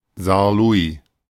Saarlouis (German: [zaːɐ̯ˈlʊɪ̯]
De-Saarlouis.ogg.mp3